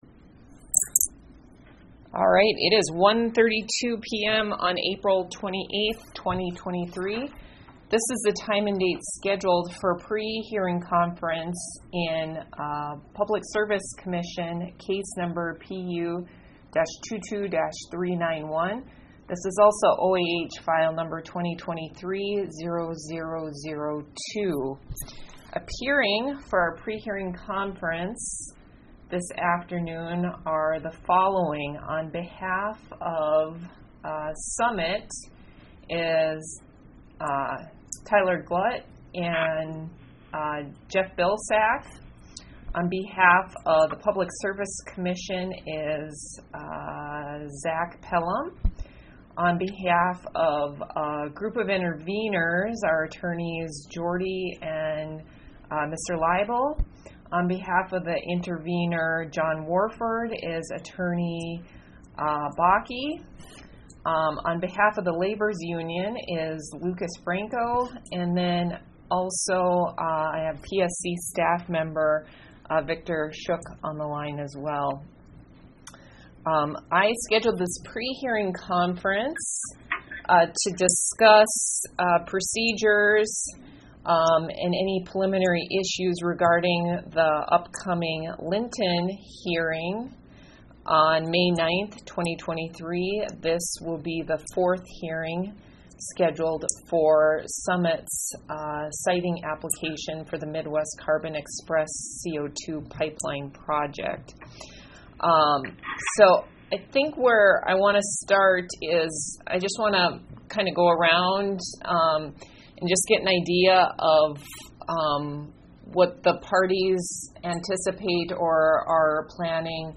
Electronic Recording of 28 April Prehearing Conference